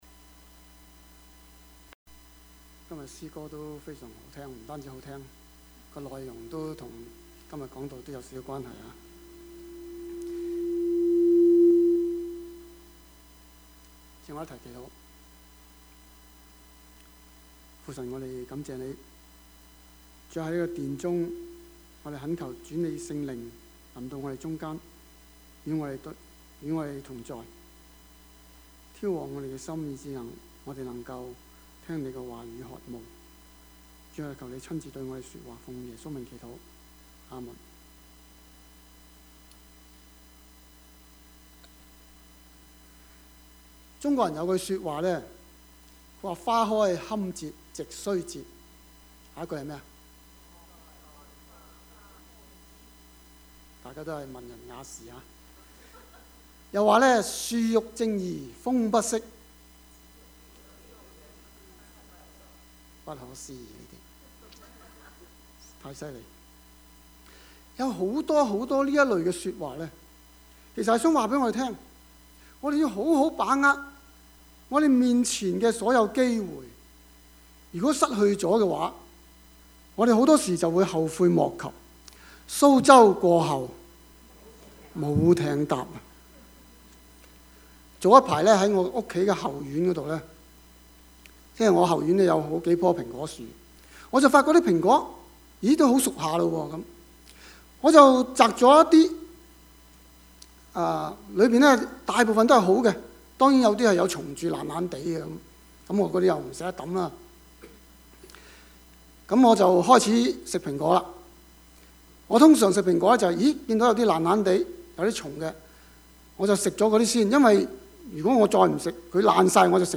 Service Type: 主日崇拜
Topics: 主日證道 « 耶和華聽見了 真割禮 »